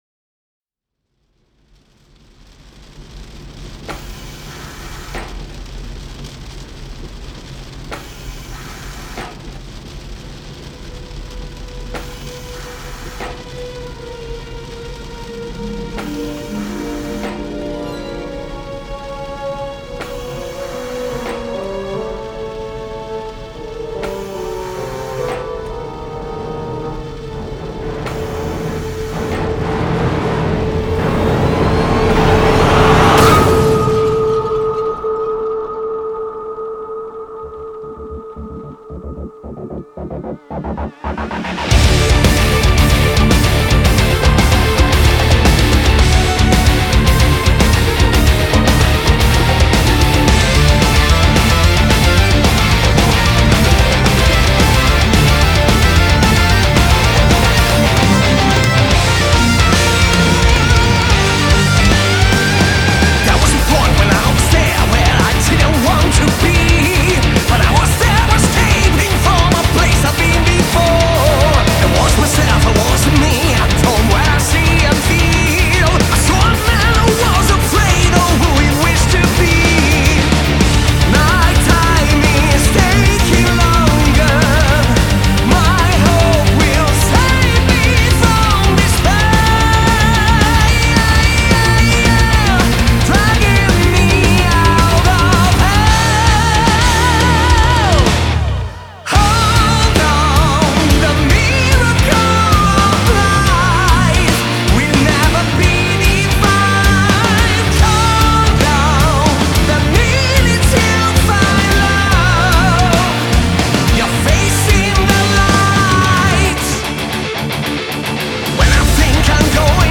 Genre: Metal
Recorded at Fascination Street Studios.